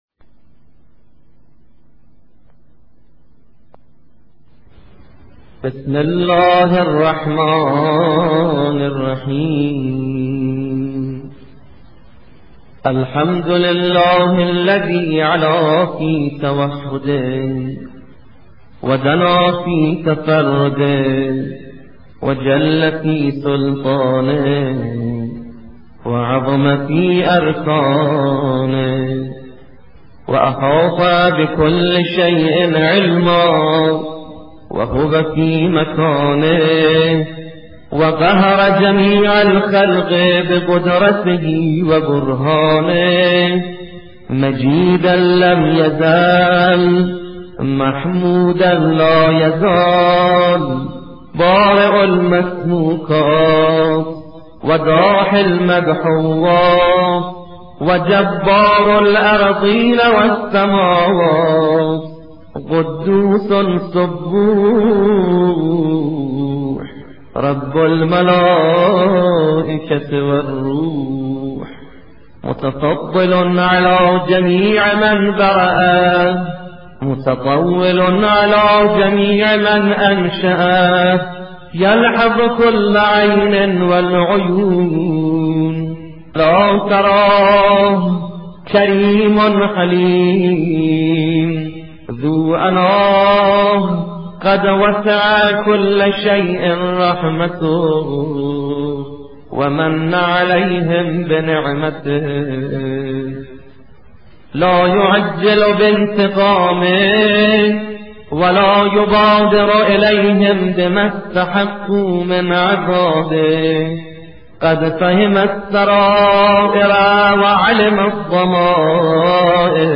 خطبه غدیر-بخش اول.mp3